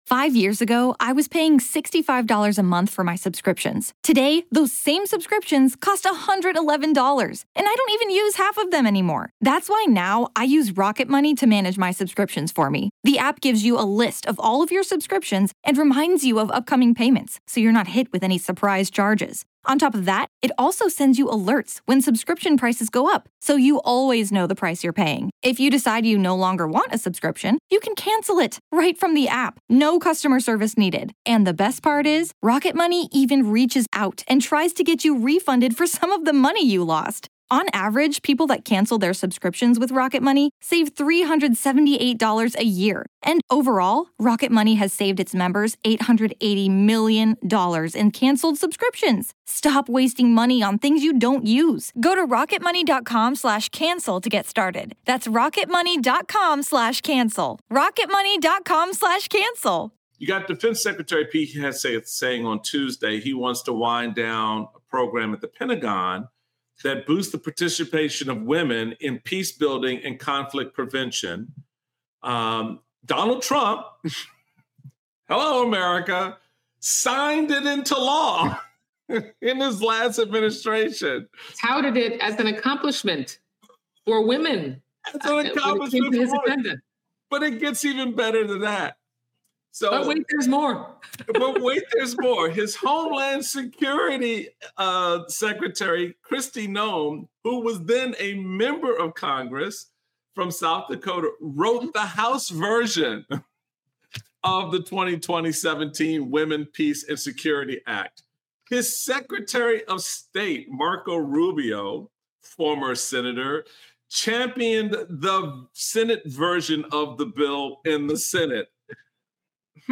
Michael Steele speaks with Florida Congresswoman Debbie Wasserman Schultz about Pete Hegseth proudly ending the "woke" Women, Peace and Security program that Trump himself signed. Plus, what tools Democrats can use to expose the incompetency of the Trump Administration.